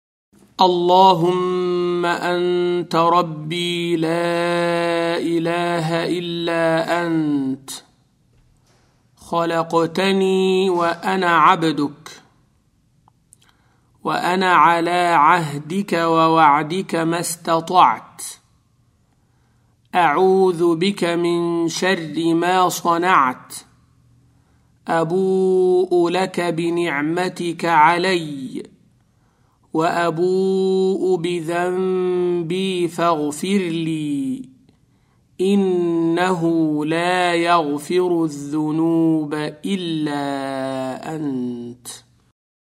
Forgiveness (Supplication)
thewahyproject-supplication-forgiveness_afdal_al_istighfar-0001-0.mp3